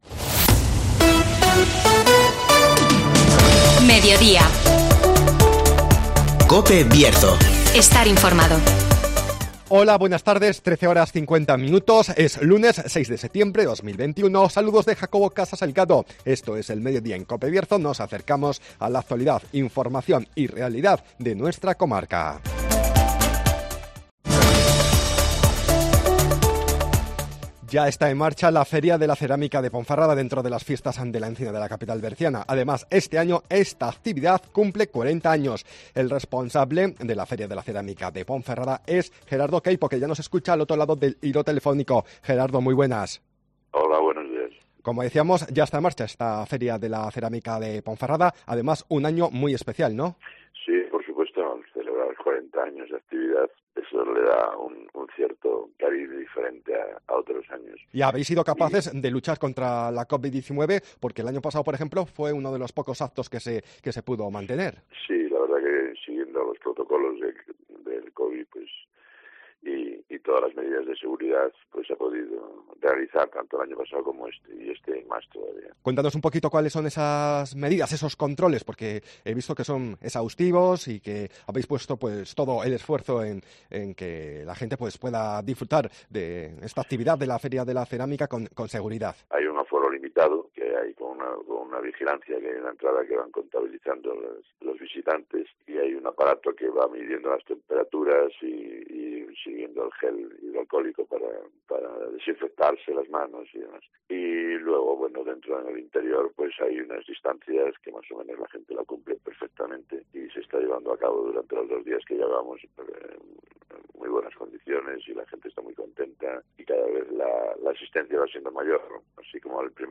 La Feria de Cerámica de Ponferrada celebra su 40 aniversario (Entrevista